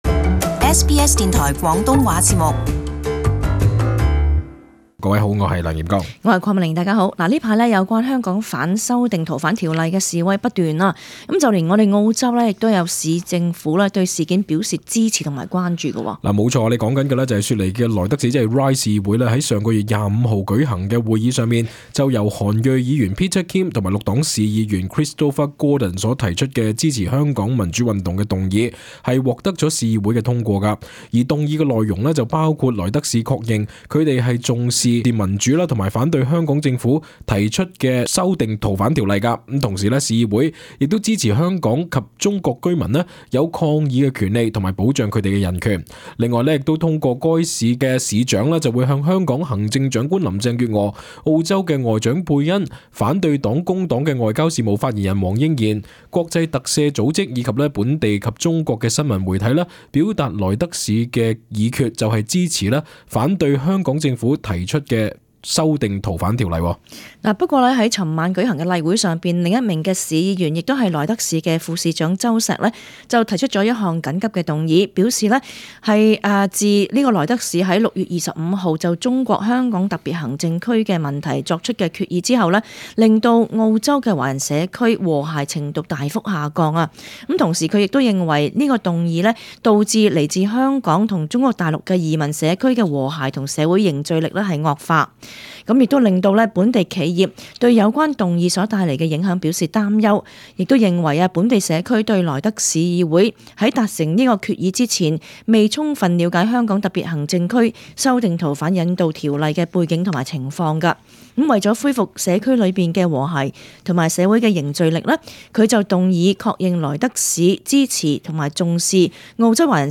Gladys Liu delivers her maiden speech in the House of Representatives at Parliament House.